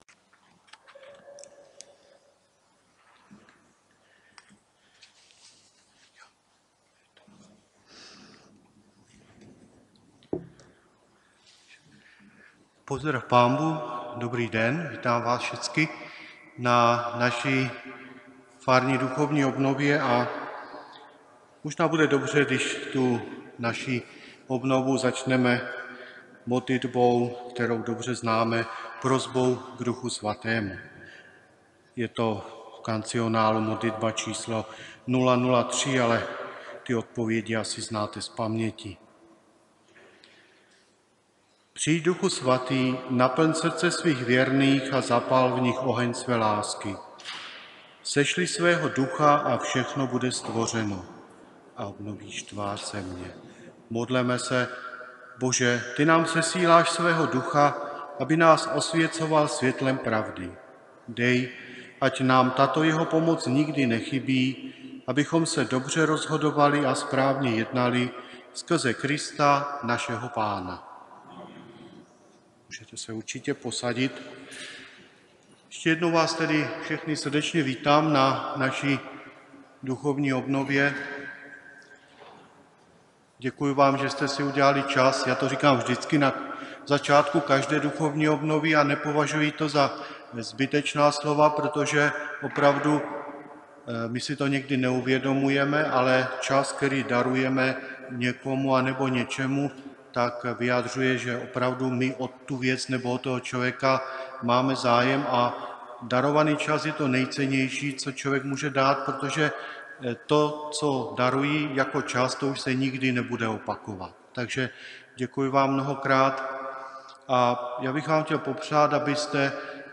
Přednášku si můžete poslechnout zde 1.přednáška a 2. přednáška .